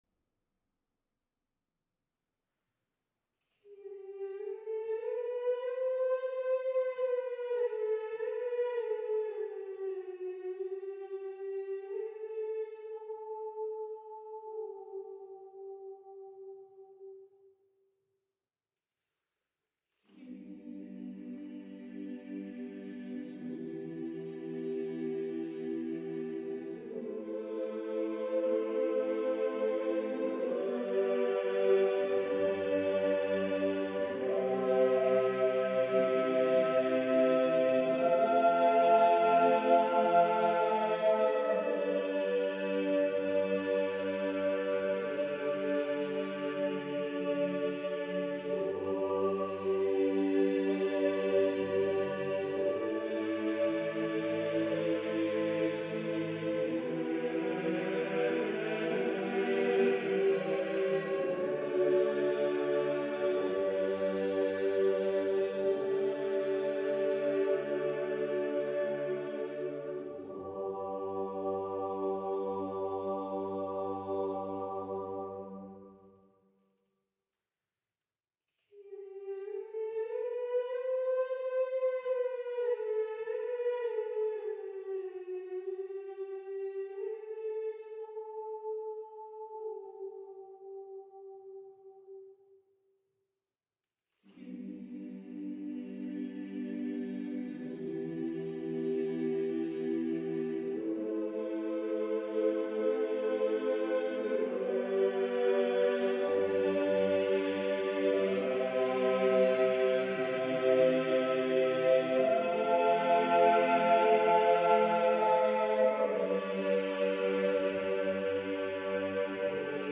Music Category:      Early Music
SSATTB or SSATBB